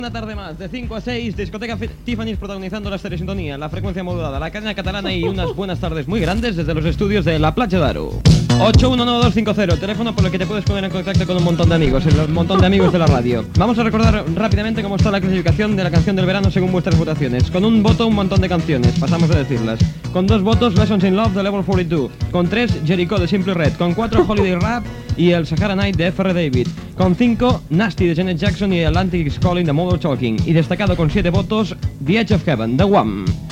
Publicitat, identificació, telèfon, classificació de la cançó de l'estiu
Musical